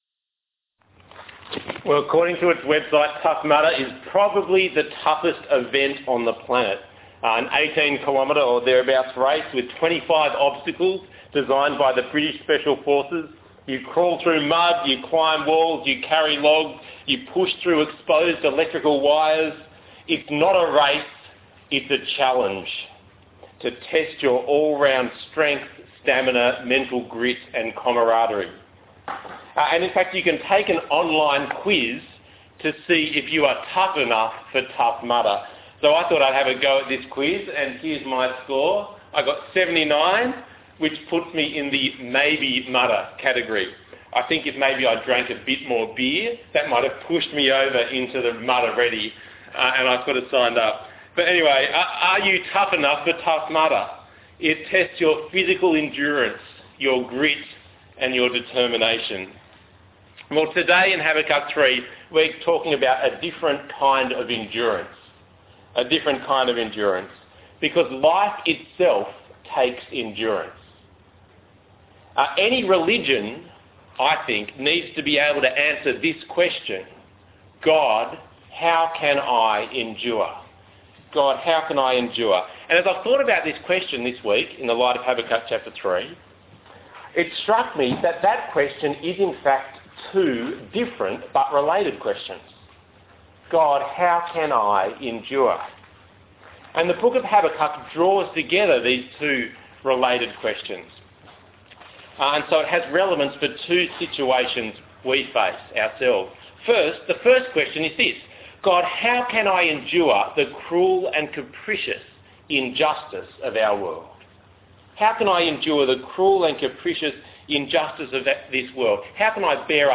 Tough Questions for Tough Times Passage: Habakkuk 3:1-19 Talk Type: Bible Talk « God